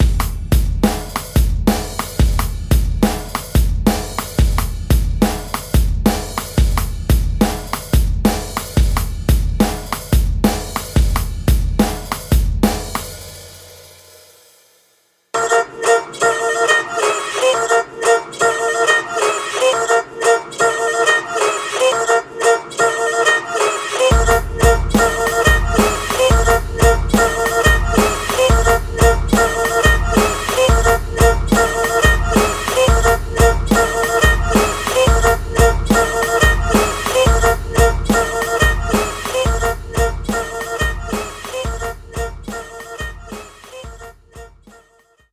Μὲ τὴ βοήθεια τῆς σύγχρονης μουσικῆς τεχνολογίας, ἐπενδύσαμε τὸ μέτρο μὲ ψηφιακοὺς ἤχους drums, ἄψογα παιγμένους μὲ τοὺς κανόνες καὶ τὶς ὑποδείξεις τοῦ συγκεκριμένου μέτρου.
Τριγωνικὸς ὁκτάρι -Ρυθμός